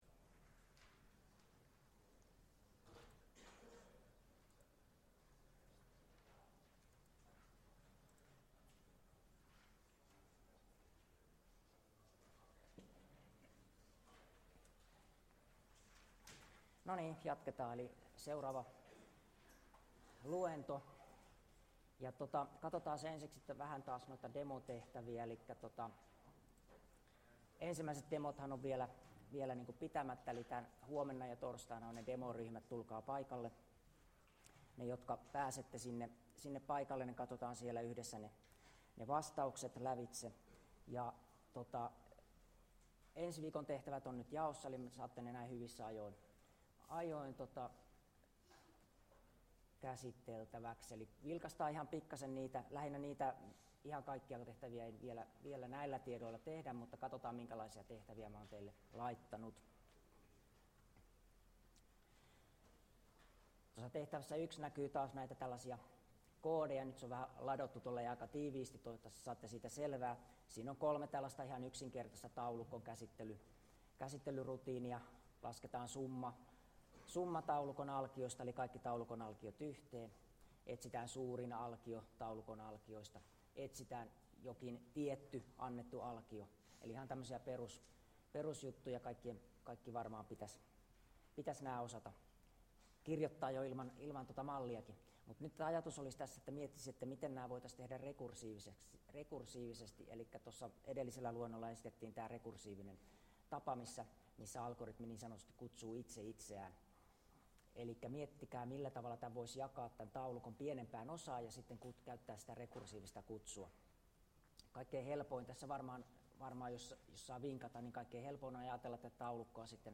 Luento 5 2c18d03d3fb44b19b8dacafd0cb776e9 ITKA201 Algoritmit 1, Kevät 2017 Luento 5 Näytä video Selaimesi ei tue äänentoistoa.